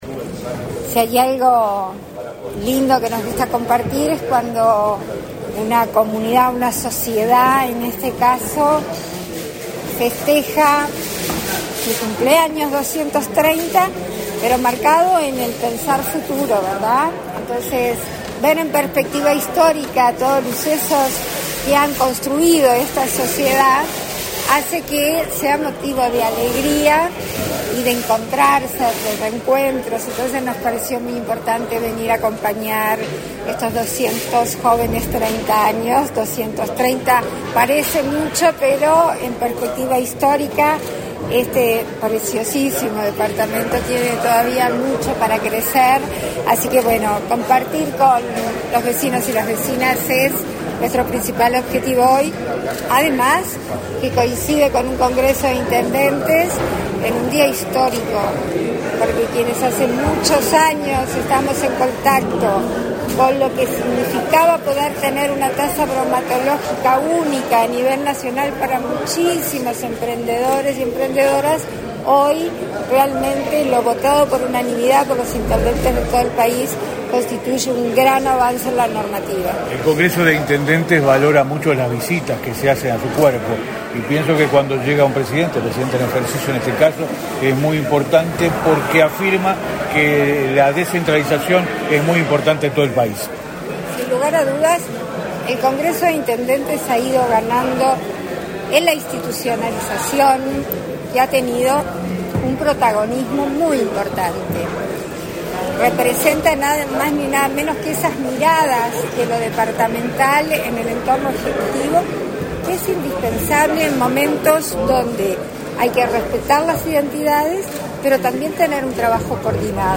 Declaraciones a la prensa de la presidenta de la República en ejercicio, Beatriz Argimón
Declaraciones a la prensa de la presidenta de la República en ejercicio, Beatriz Argimón 23/11/2023 Compartir Facebook X Copiar enlace WhatsApp LinkedIn Tras participar en la reunión del Congreso de Intendentes en Rocha, este 23 de noviembre, la presidenta de la República en ejercicio, Beatriz Argimón, realizó declaraciones a la prensa.
argimon prensa.mp3